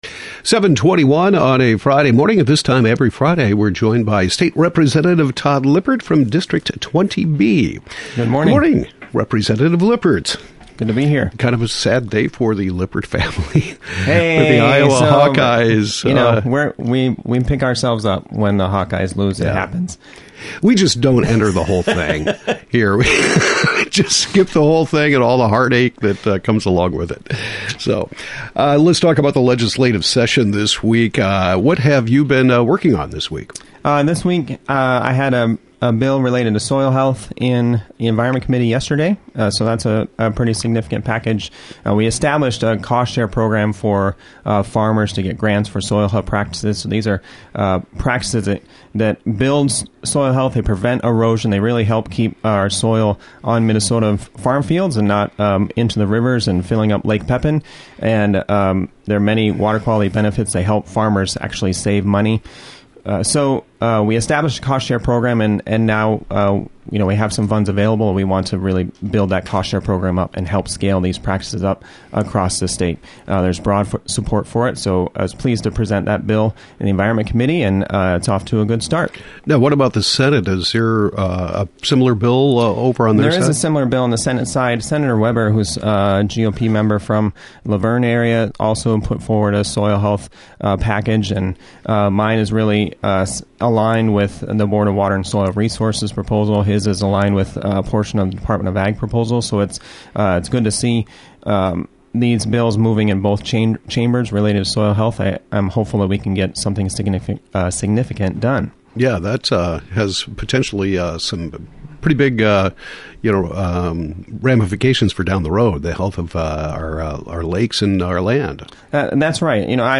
Representative Todd Lippert gives update on legislative activities